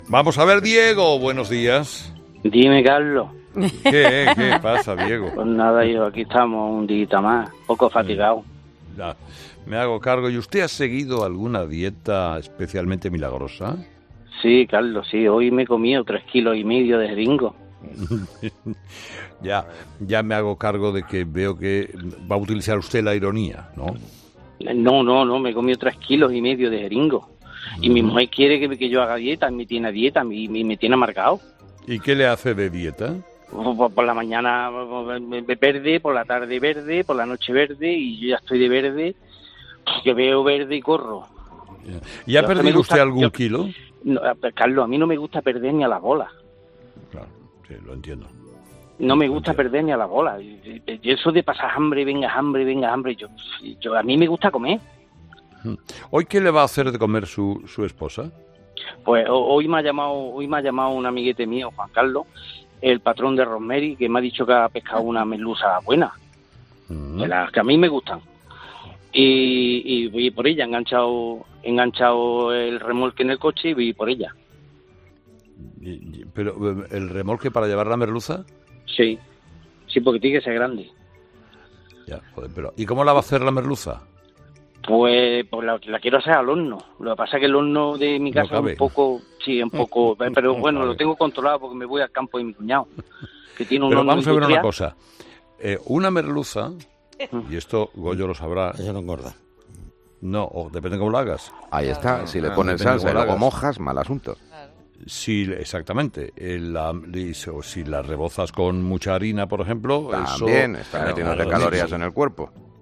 Este martes los 'fósforos' de Herrera han hablado sobre nutrición.